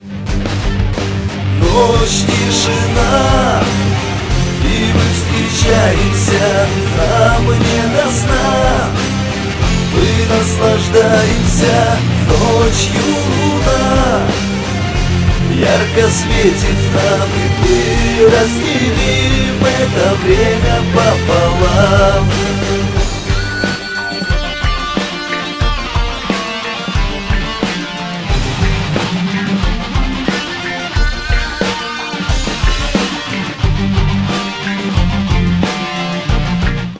• Качество: 128, Stereo
красивая мелодия
русский рок